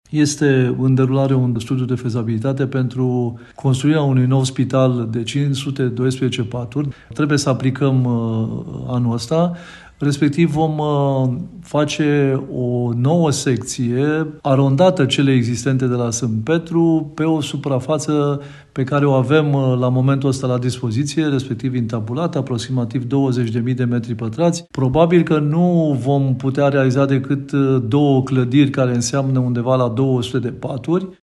Vicepreședintele Consiliului Județean Brașov, Șerban Todorică: